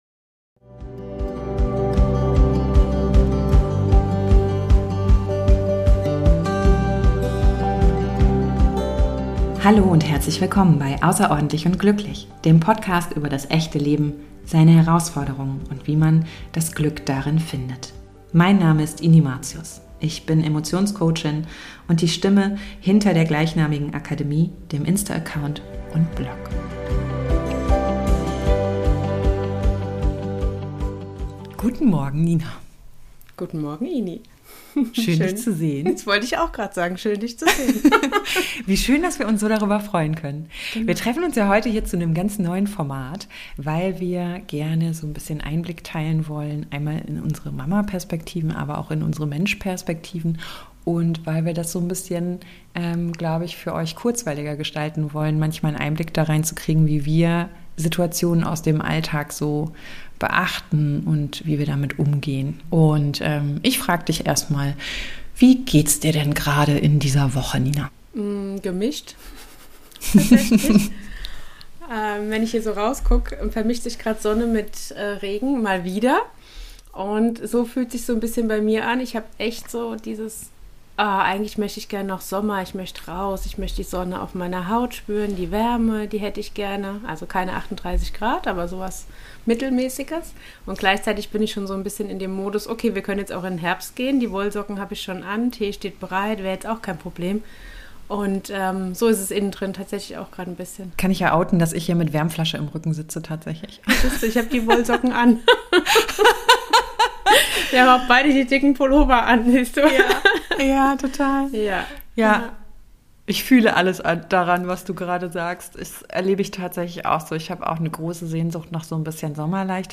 Beschreibung vor 8 Monaten In unserem Format Alltag teilen mit kannst du uns zuhören, wie wir uns auf der bodenständigen Ebene ehrlicher pflegender Mutterschaft austauschen.